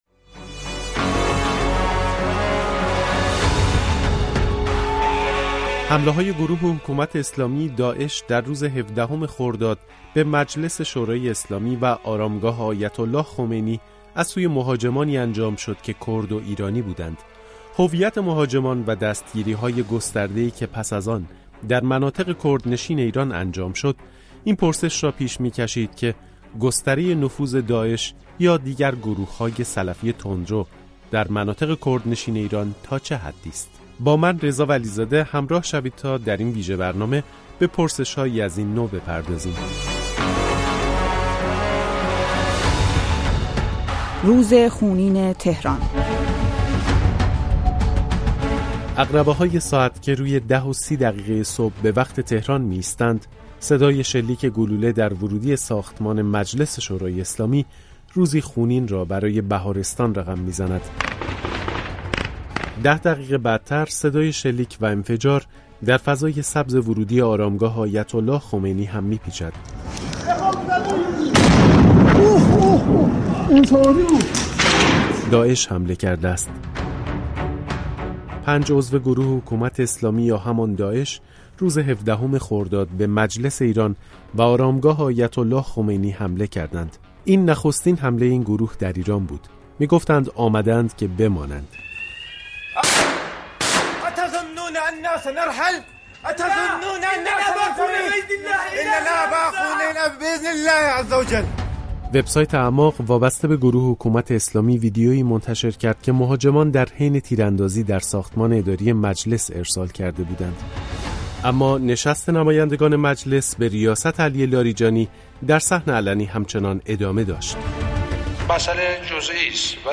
گزارشی ویژه